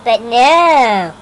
But Nooo Sound Effect
Download a high-quality but nooo sound effect.